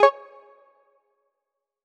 match-ready.wav